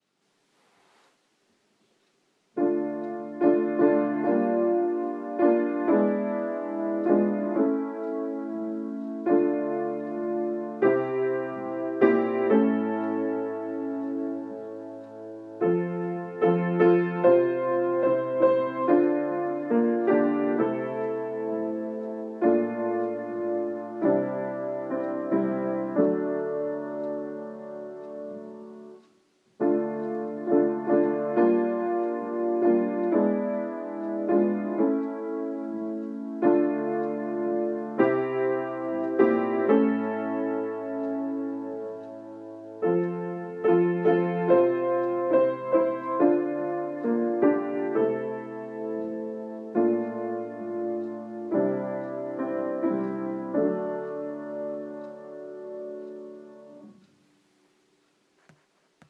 Le fichier .mp3 propose un accompagnement piano du chant.